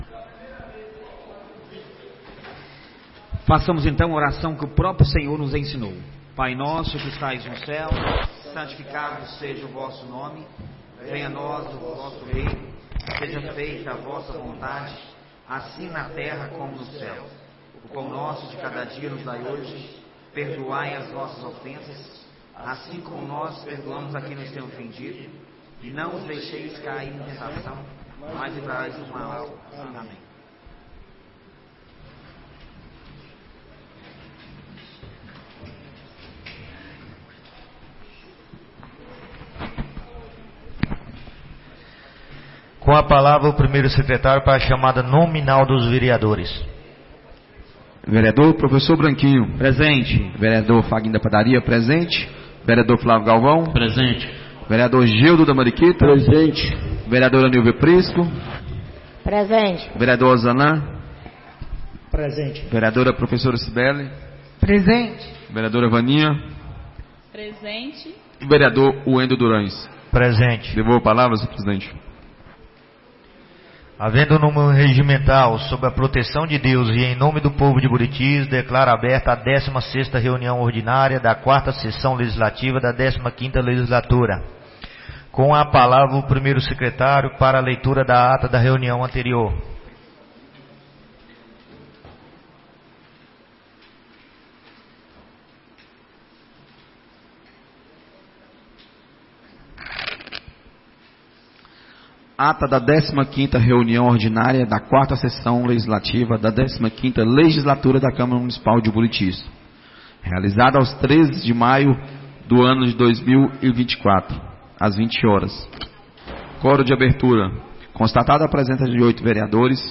16ª Reunião Ordinária da 4ª Sessão Legislativa da 15ª Legislatura - 20-05-24